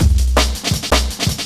Помогите найти откуда семплировали брейк
Брейк взят с нонейм диска из нулевых.